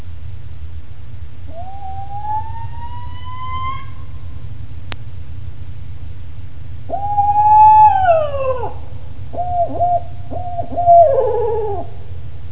Imagine your walking in the woods, it pitch dark, your picking up sticks to build a fire and you here this.
BarredOwl.wav